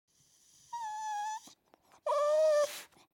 جلوه های صوتی
دانلود صدای سگ 14 از ساعد نیوز با لینک مستقیم و کیفیت بالا
برچسب: دانلود آهنگ های افکت صوتی انسان و موجودات زنده دانلود آلبوم صدای انواع سگ از افکت صوتی انسان و موجودات زنده